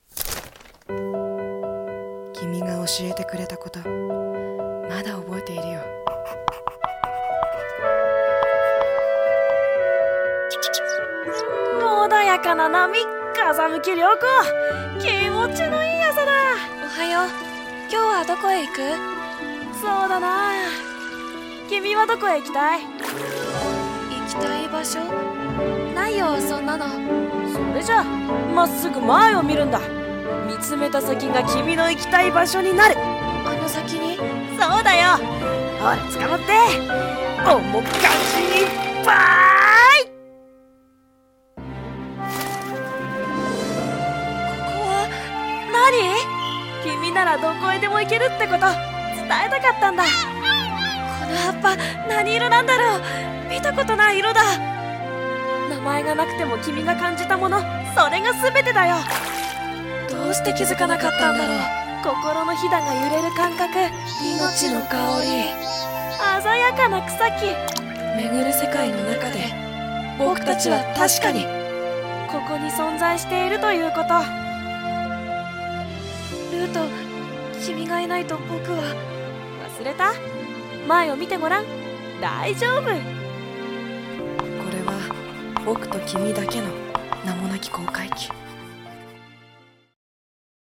【声劇】名前のない航海記